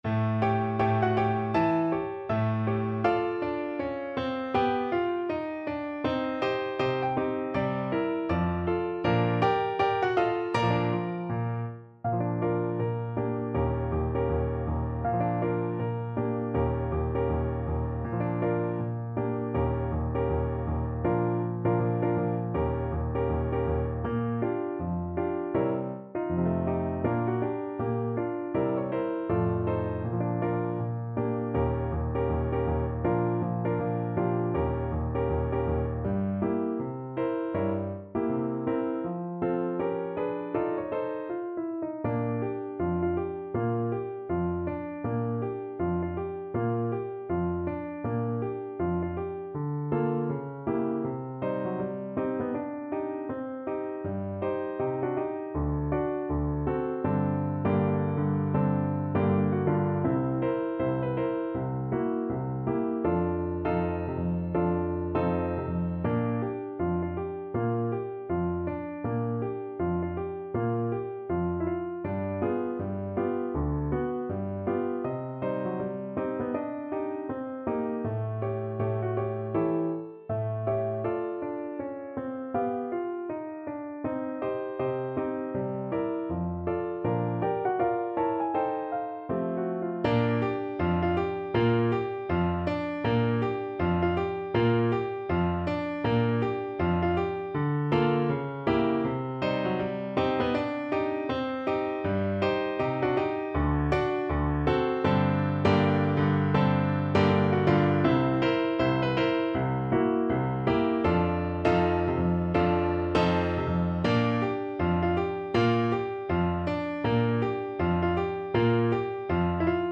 ~ = 160 Moderato
Jazz (View more Jazz Clarinet Music)